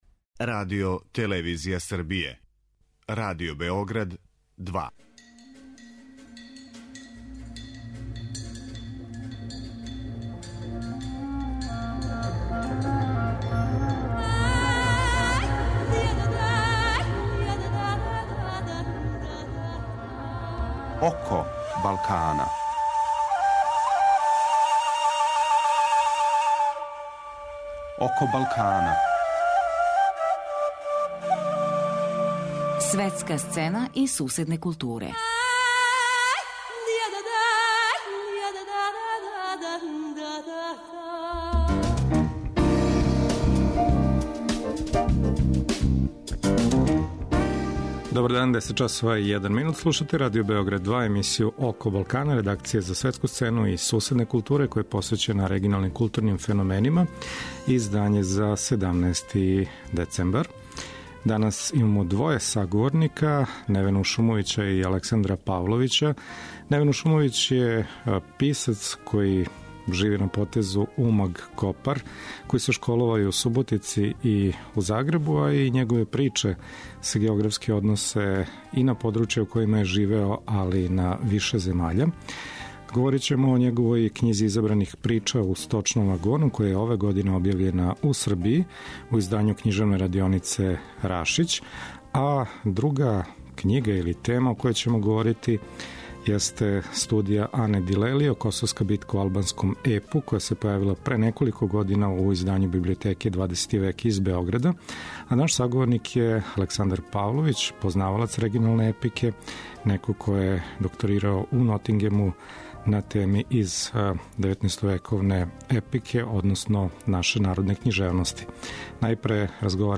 Eмисија је посвећена регионалним културама.